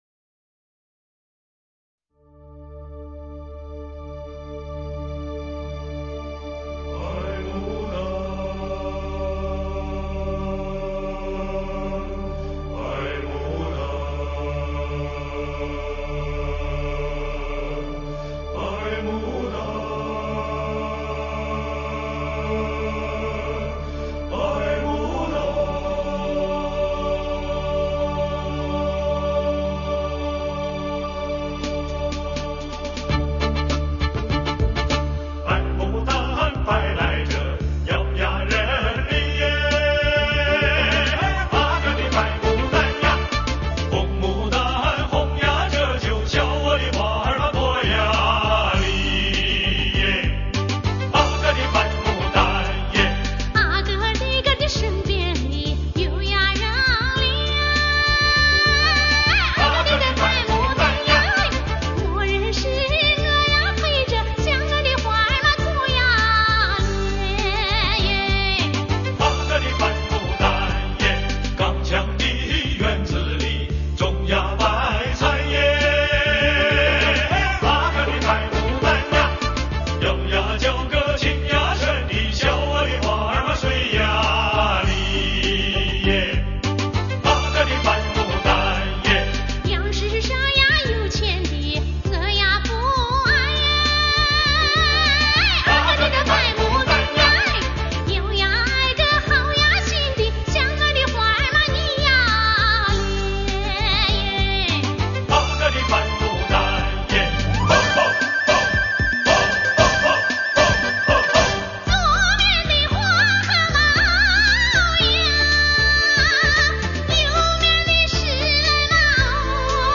大家听听这是什么歌，应该是民歌 - 音乐空间 - 江阴论坛 - Powered by Discuz!